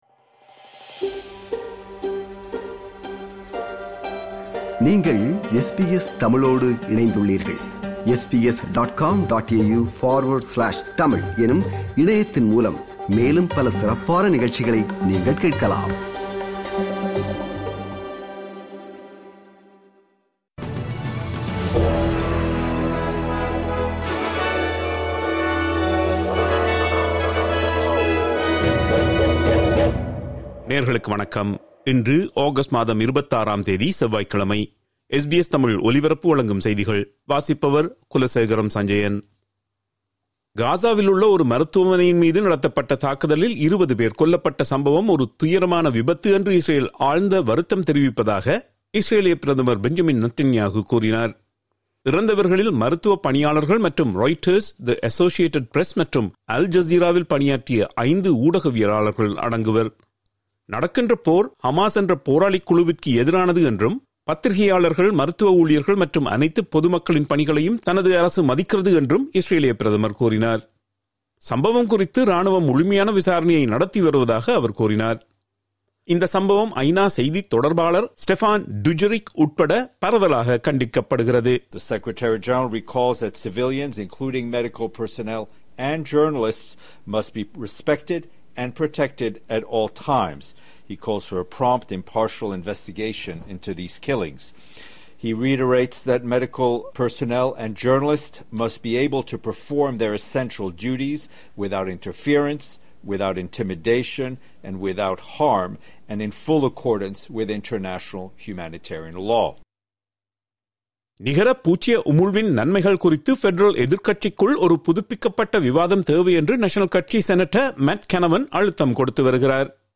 SBS தமிழ் ஒலிபரப்பின் இன்றைய (செவ்வாய்க்கிழமை 26/08/2025) செய்திகள்.